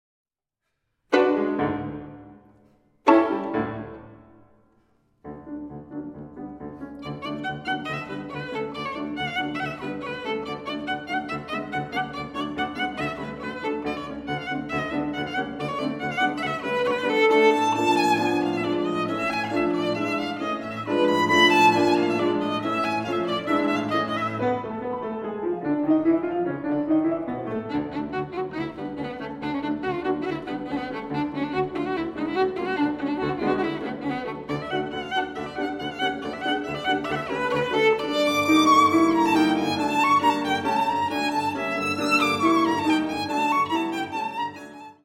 miniatures for violin and piano
piano